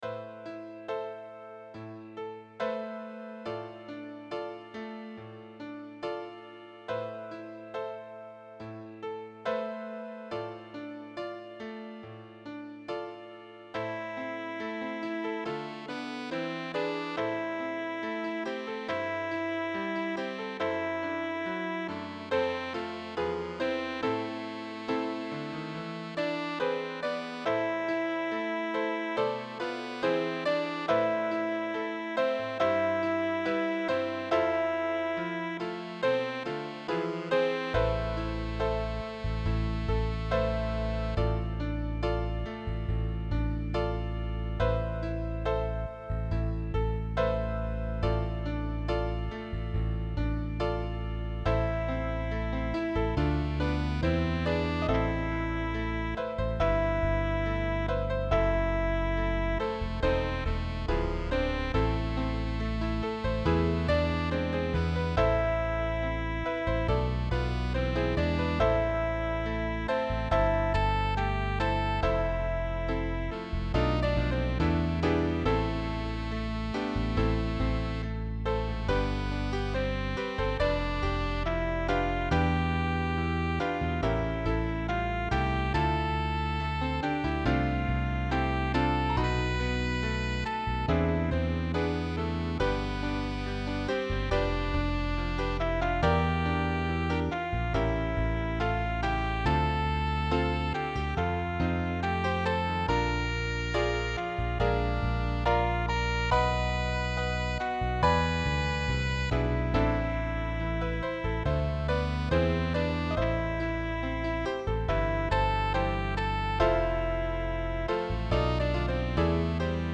SAX CONTRALTO
• accompagnamento piano - basso - base Mp3
Sax alto
piano
bass